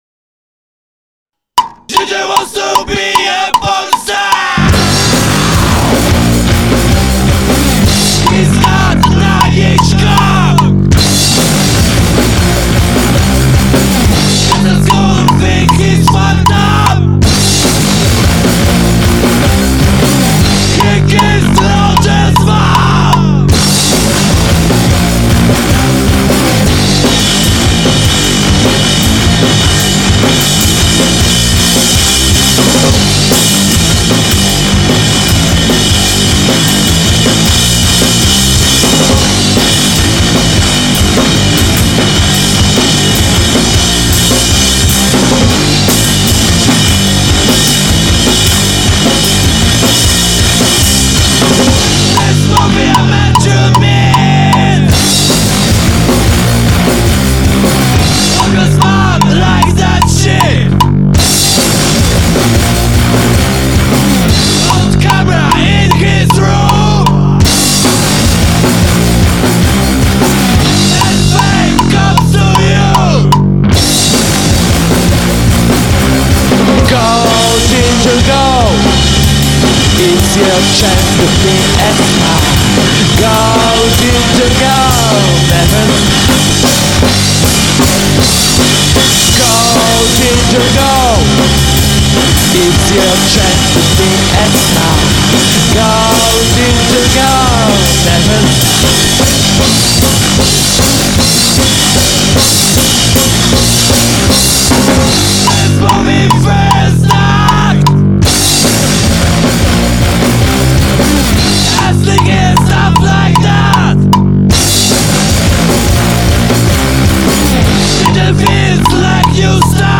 Gatunek: Regresywny Rock'n'Roll